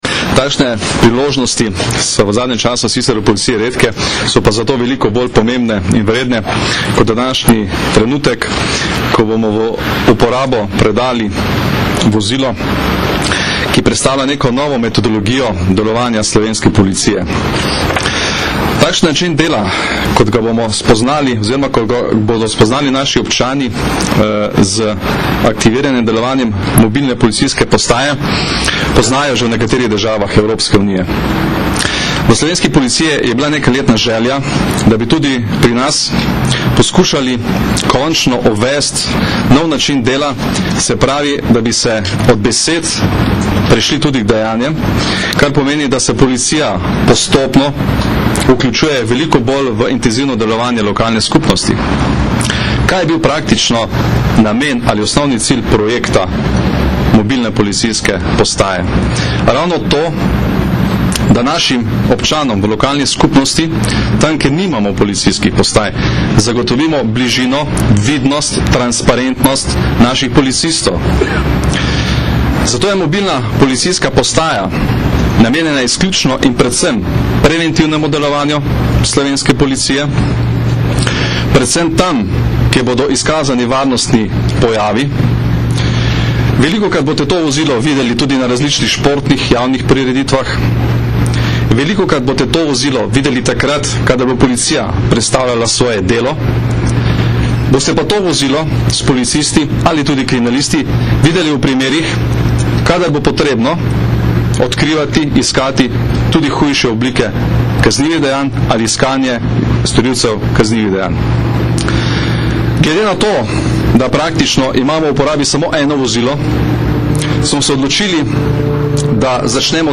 Generalni direktor policije Janko Goršek je danes, 4. januarja 2012, direktorju Policijske uprave Kranj Simonu Veličkemu simbolično predal ključe mobilne policijske postaje.
Zvočni posnetek nagovora generalnega direktorja policije